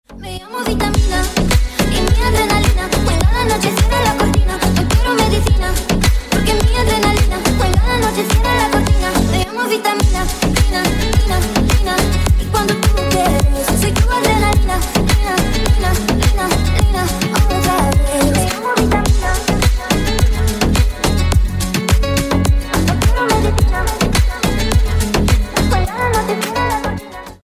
Латинские Рингтоны
Рингтоны Ремиксы » # Танцевальные Рингтоны